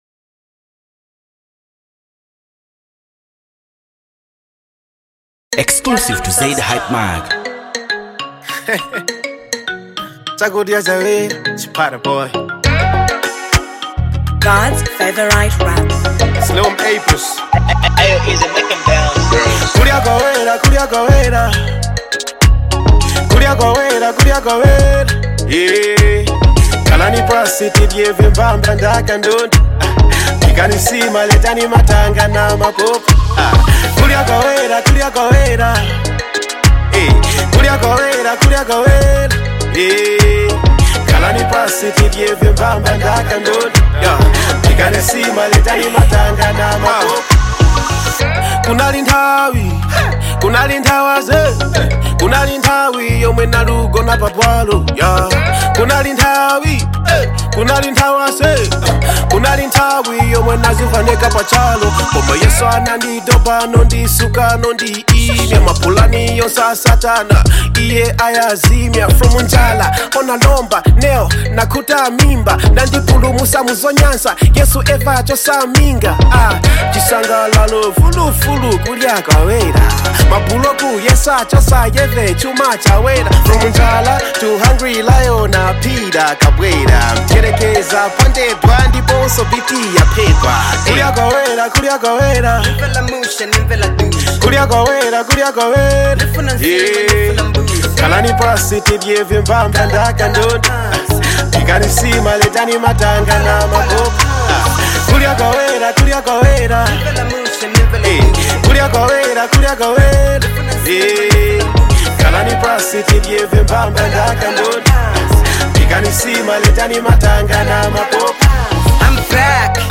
Christian HipHop